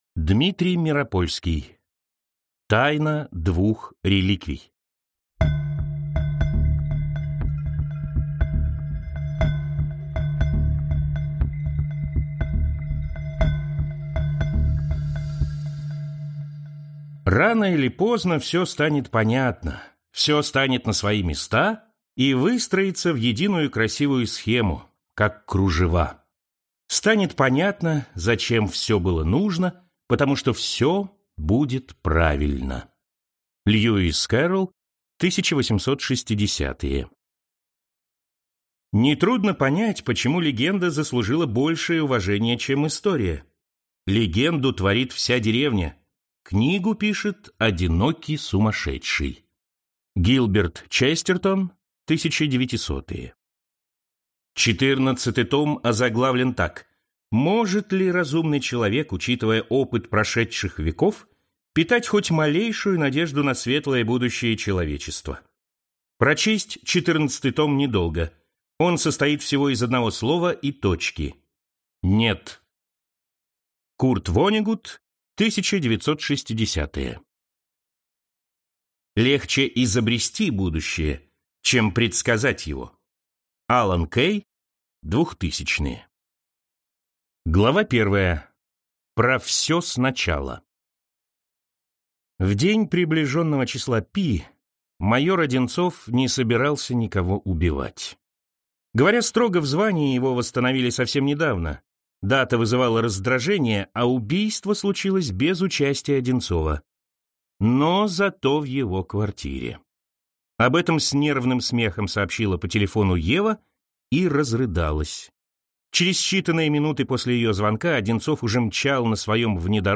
Аудиокнига Тайна двух реликвий | Библиотека аудиокниг